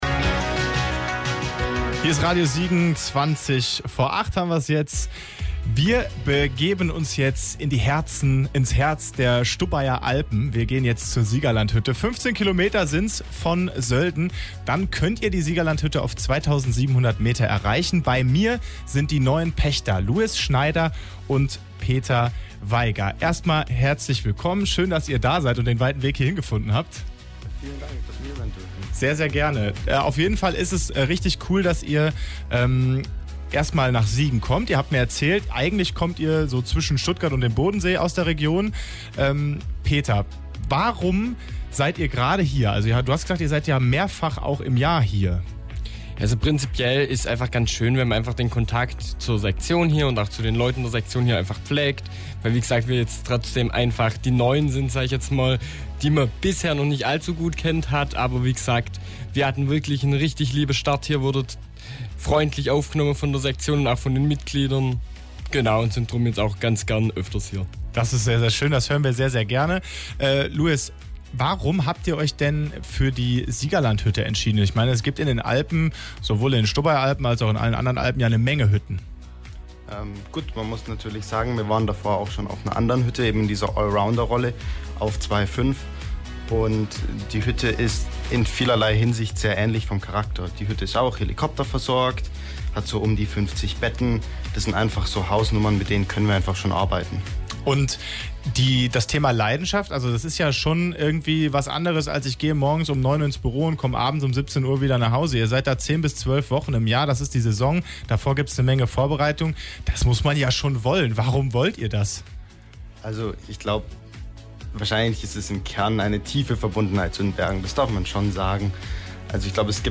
Radiomitschnitt vom 13.02.2026 (mp3) Quelle: Radio Siegen